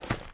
STEPS Dirt, Run 08.wav